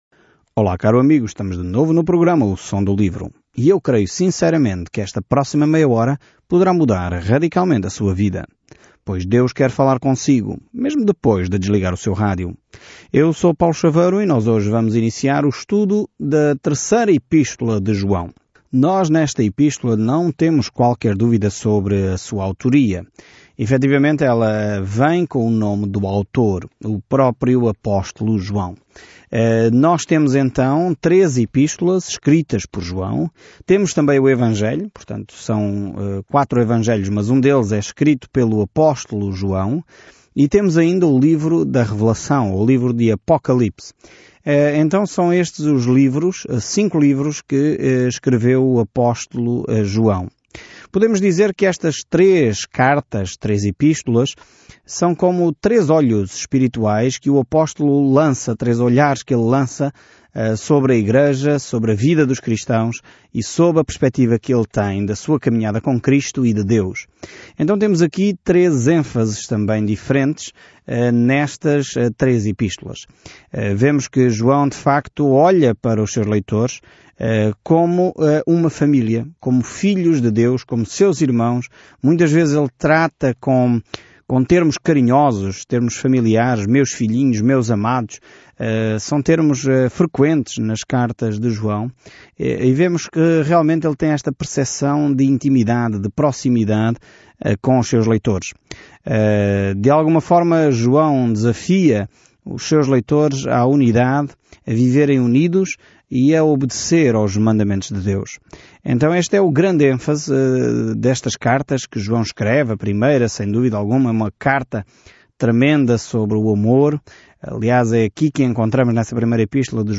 Escritura 3 JOÃO 1:1-2 Iniciar este Plano Dia 2 Sobre este plano Andem na verdade e amem uns aos outros – essa é a mensagem da terceira carta de João sobre como apoiar a verdade e como lidar com os falsos mestres. Viaje diariamente por 3 João enquanto ouve o estudo em áudio e lê versículos selecionados da palavra de Deus.